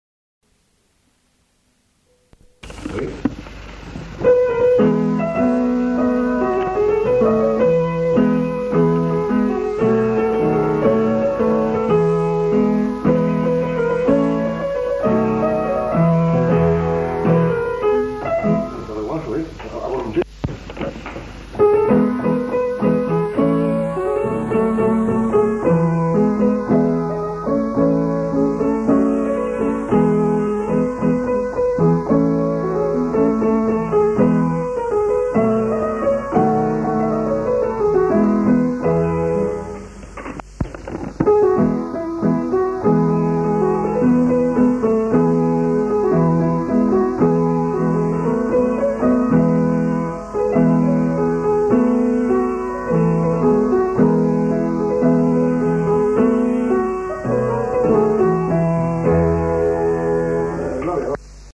Airs à danser interprétés à l'accordéon diatonique
enquêtes sonores